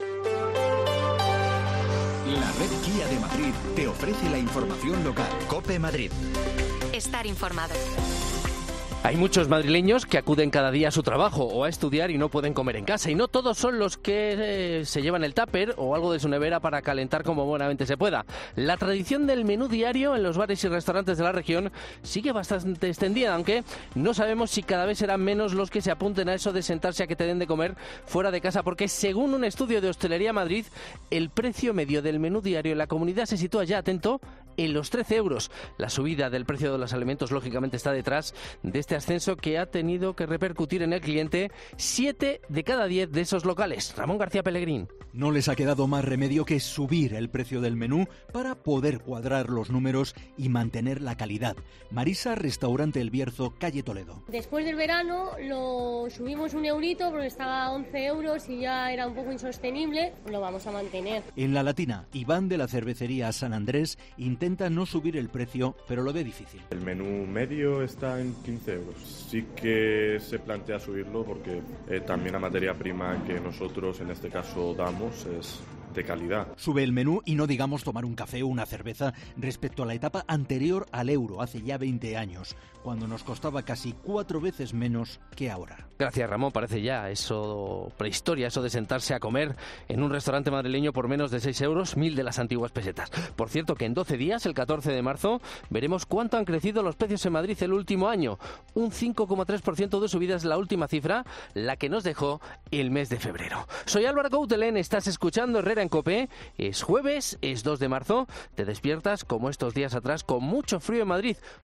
'COPE Madrid' habla con varios dueños de restaurantes acerca del aumento del precio del menú y la razón que hay tras esta subida de precios